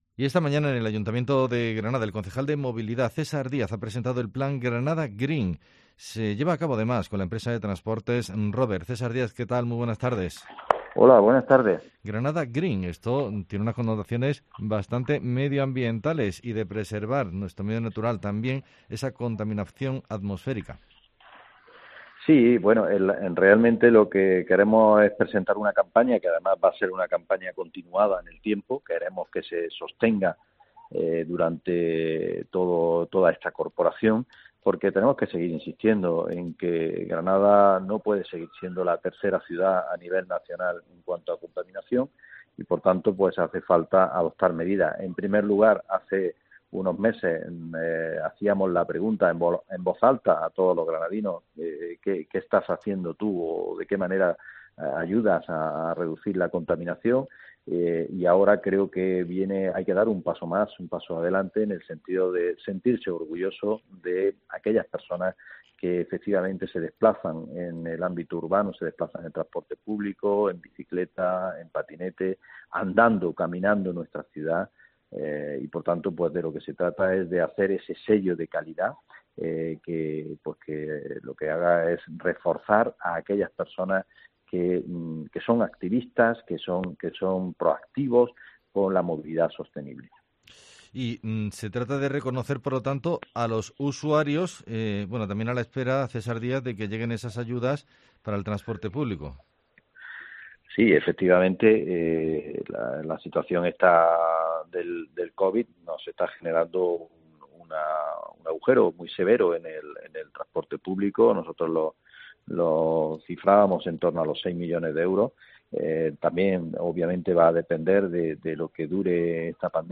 AUDIO: El concejal de movilidad nos habla también de los carriles reservados